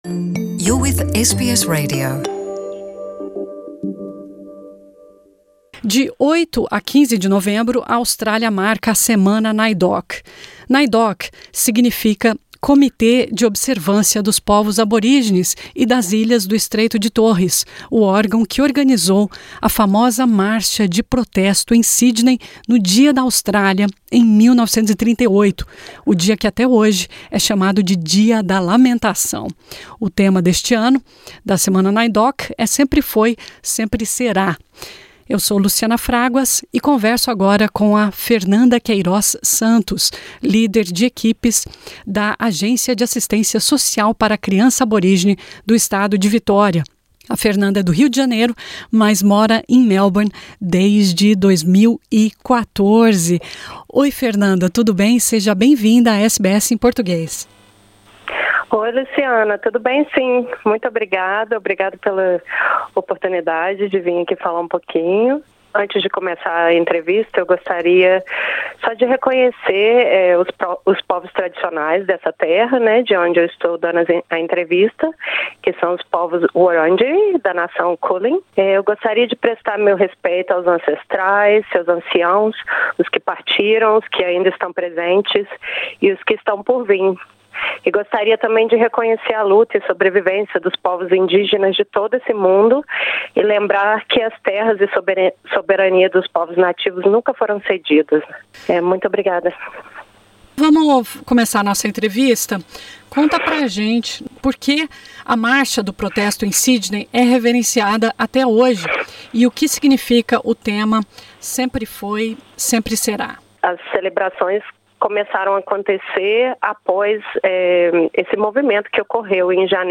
Veja a seguir os principais trechos da nossa entrevista.